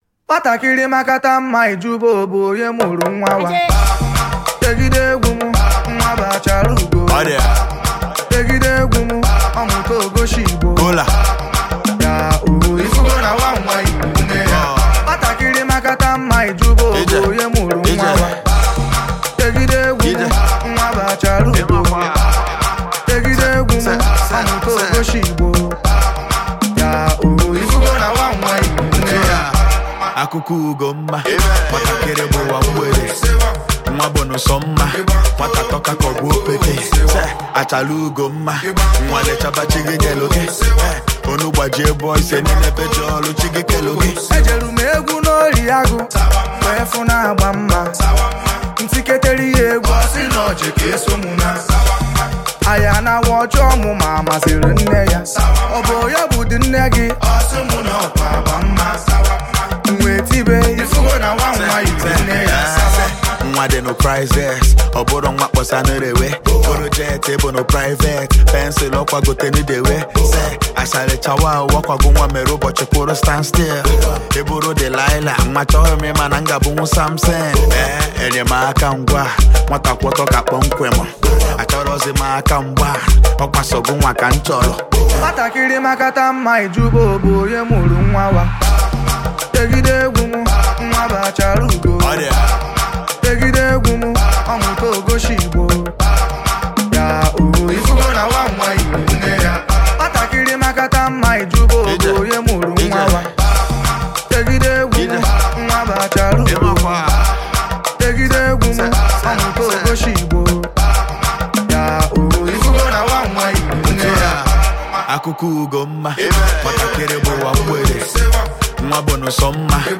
high-tempo banger